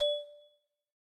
ve_abstracttiles_lock.ogg